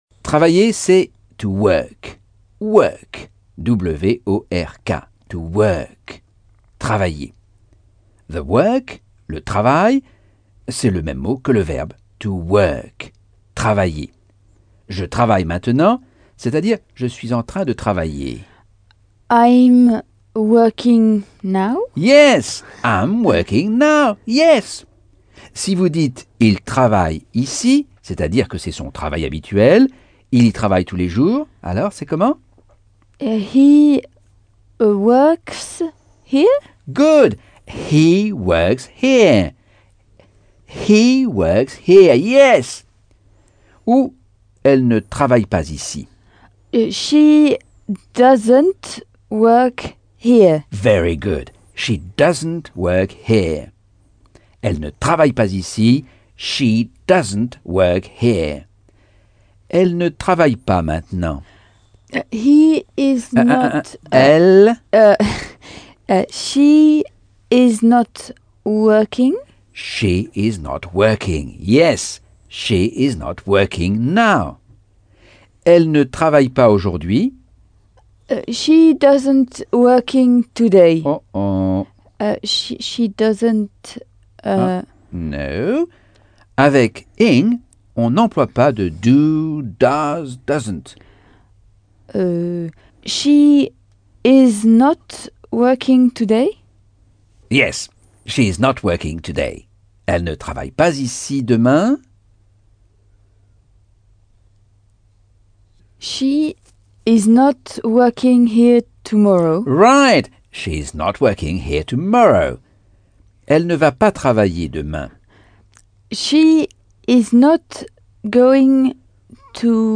Leçon 6 - Cours audio Anglais par Michel Thomas